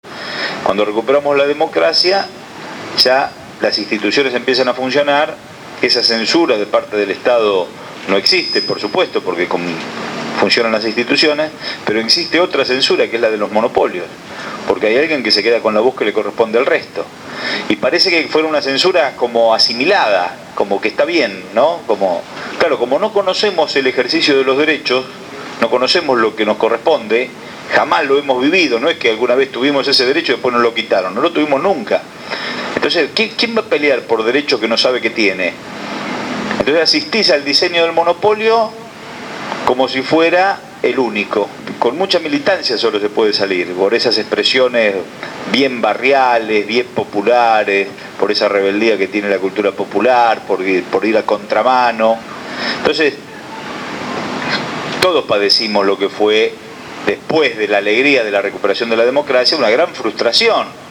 Gabriel Mariotto con los trabajadores del hospital Posadas.